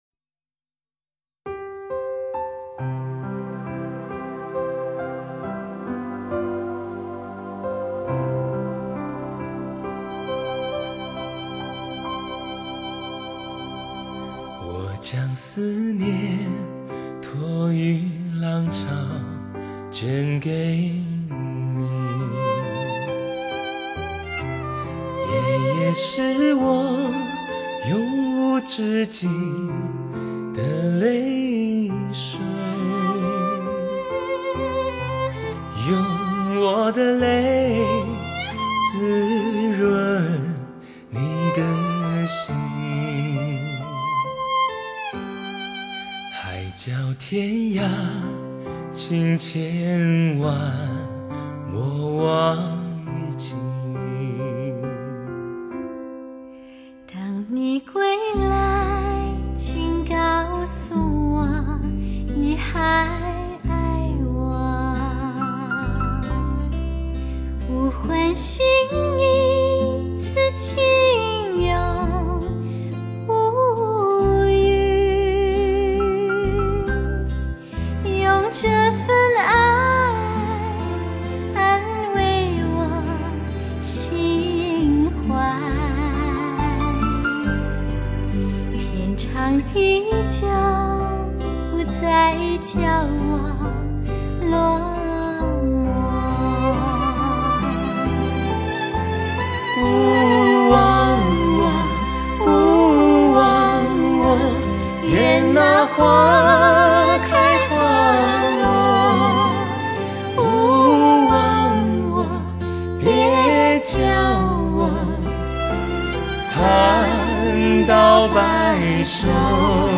无法抗拒魅力男声，登峰造级之作，尽现完美无瑕的音色， 阳光般的嗓音，深情厚爱的演唱，带你感受爱的浪漫气息 。